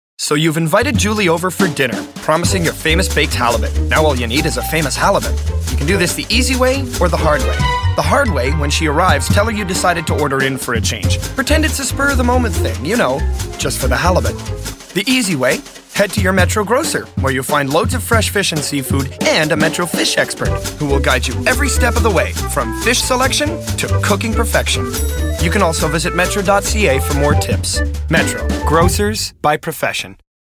Publicité (Metro #2) - ANG